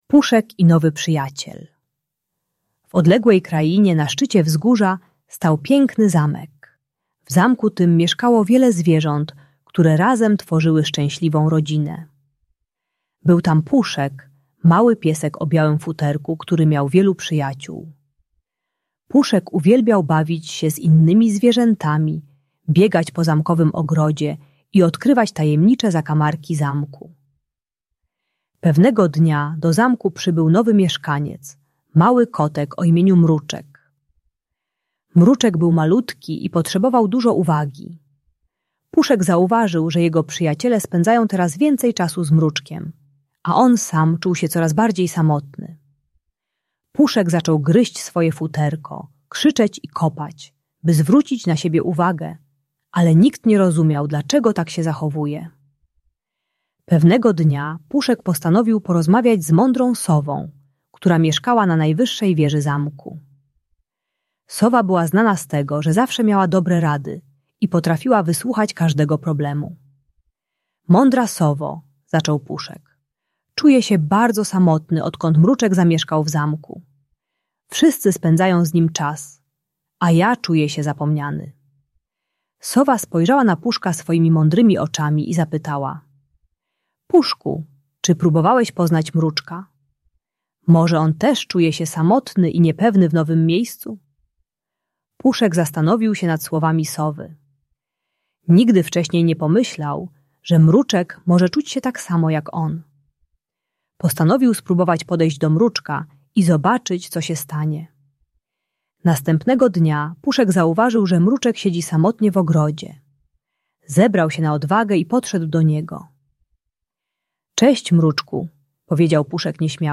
Puszek i Nowy Przyjaciel - Rodzeństwo | Audiobajka